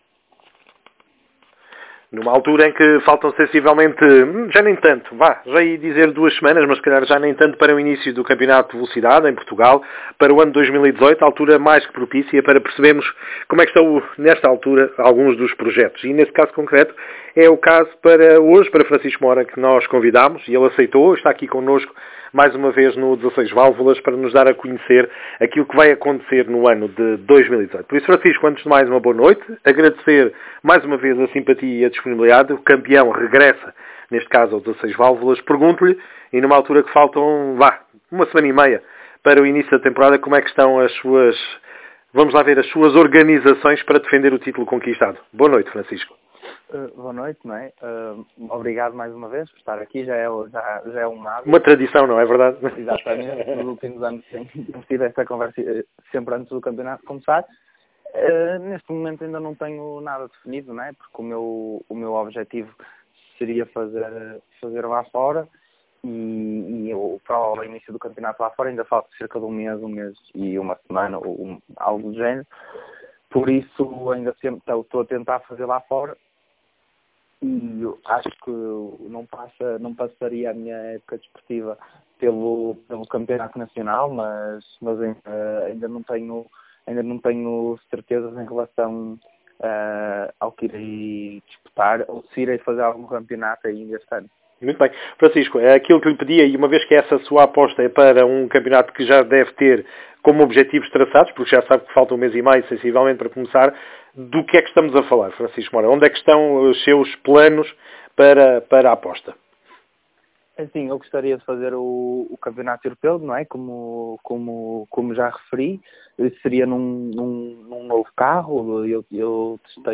Entrevista áudio